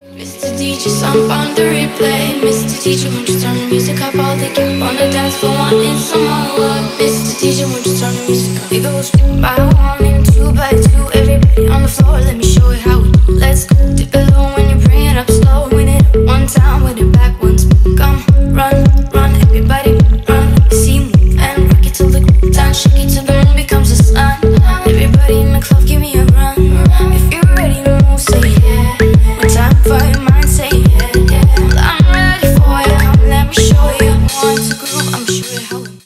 Танцевальные # кавер
клубные